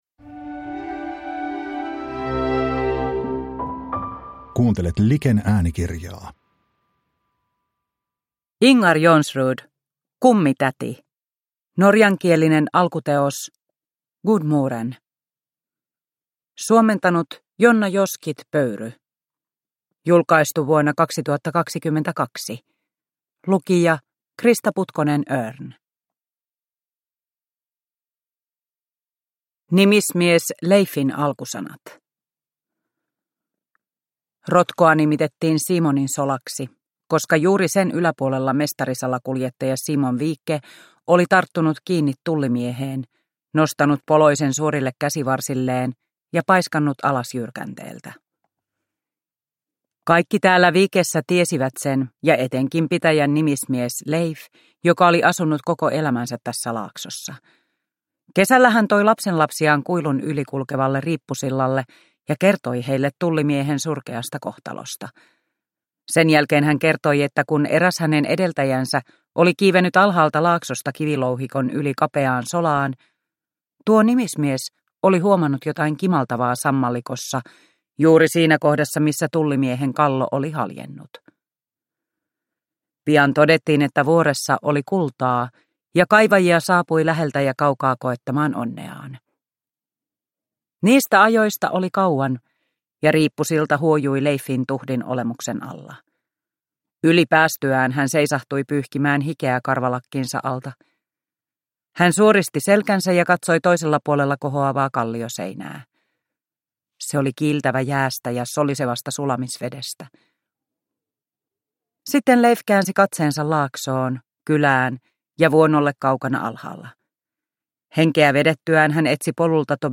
Kummitäti – Ljudbok – Laddas ner